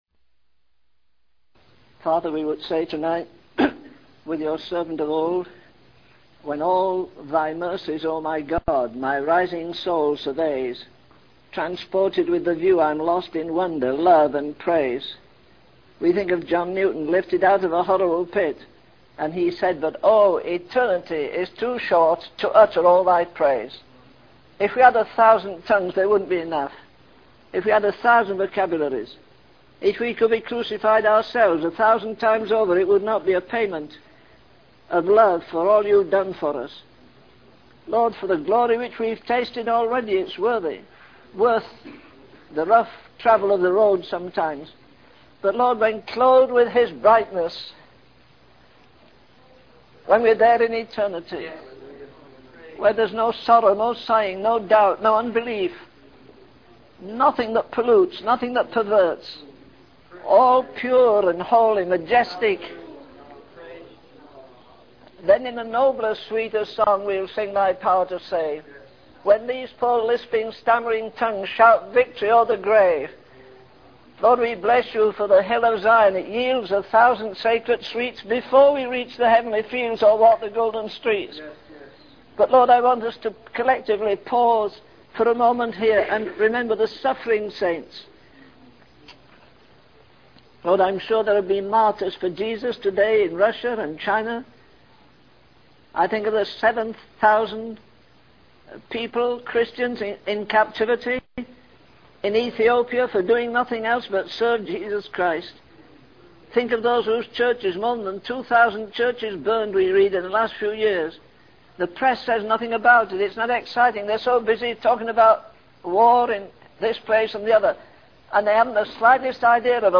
In this sermon, the preacher begins by expressing gratitude for God's mercies and praises Him.